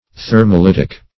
Search Result for " thermolytic" : The Collaborative International Dictionary of English v.0.48: Thermolytic \Ther`mo*lyt"ic\ (th[~e]r"m[-o]*l[i^]t"[i^]k), a. Of or pertaining to thermolysis.